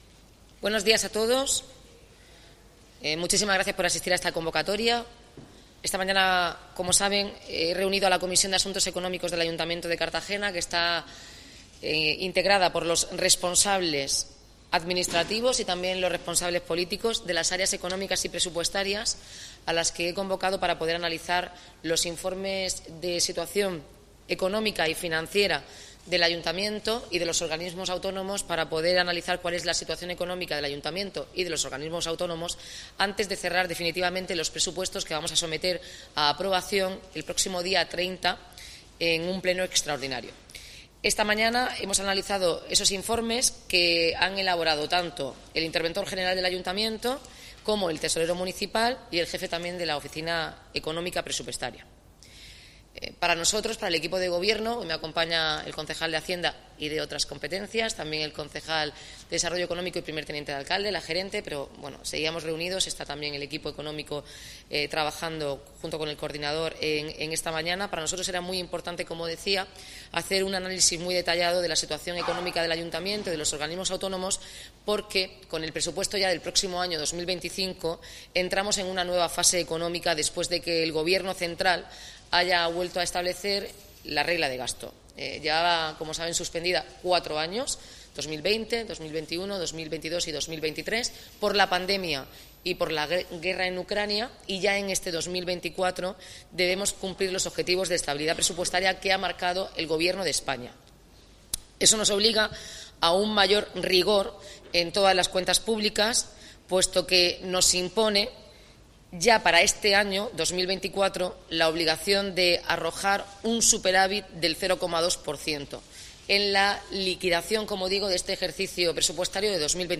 Enlace a Declaraciones de la alcaldesa, Noelia Arroyo, tras la reunión de la Comisión de Asuntos Económicos